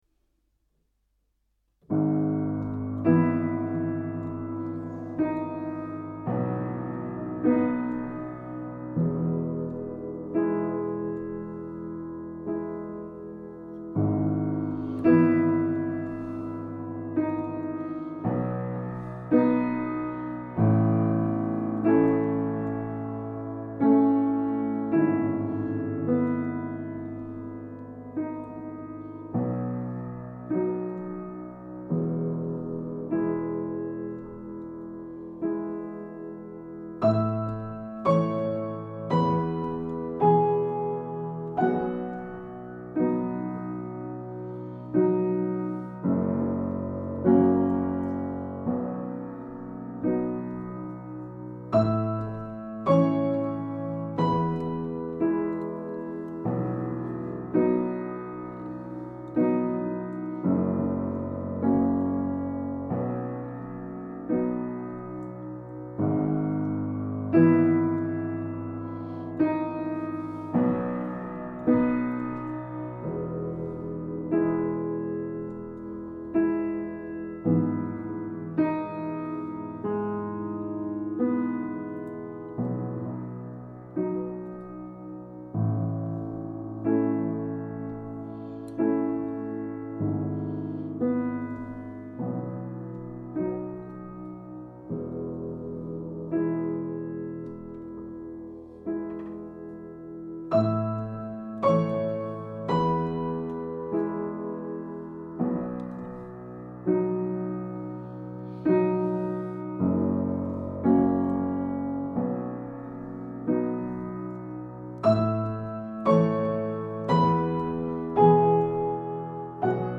skizze zum nachspielen am pianoforte
mit viel pedal zu spielen